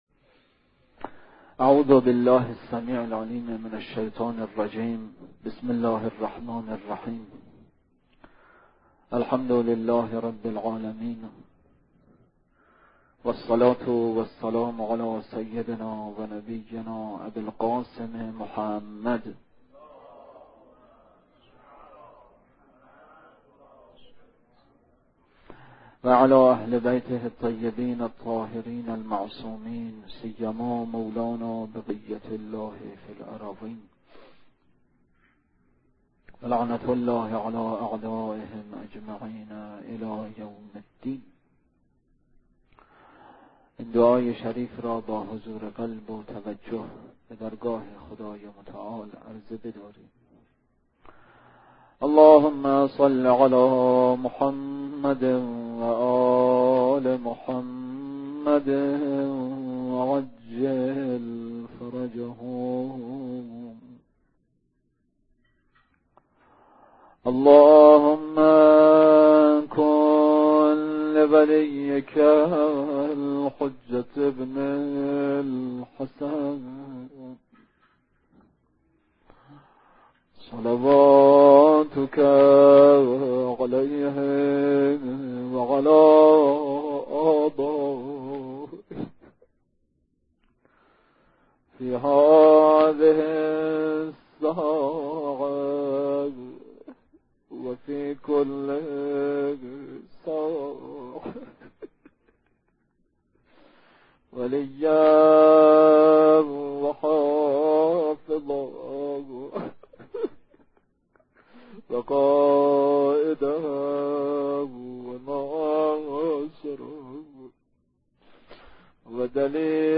سخنرانی
روضه: روضه‌ی جناب حر مرورگر شما فایل صوتی را پشتیبانی نمی کند.